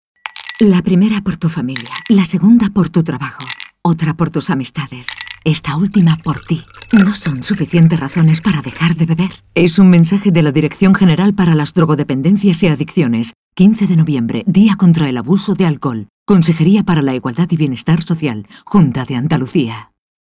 1. Emisión de cuñas de radio: